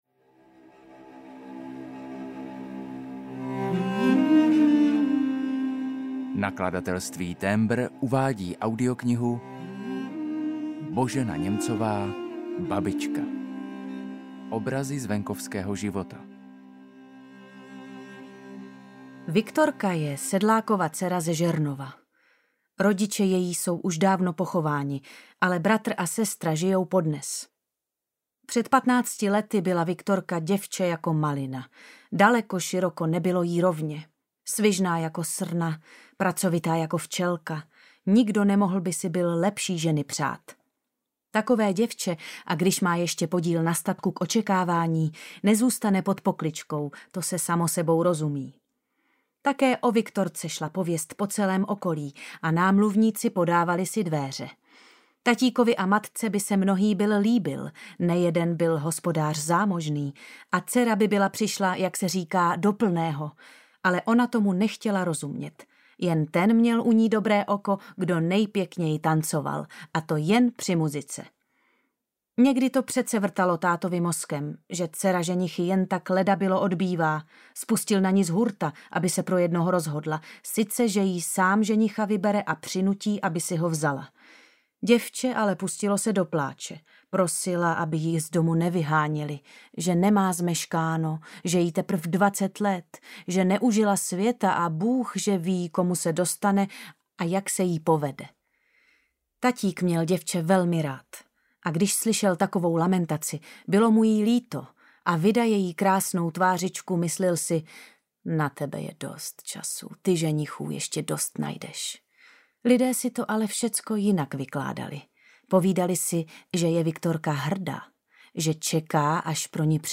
Babička audiokniha
Ukázka z knihy
• InterpretMagdaléna Borová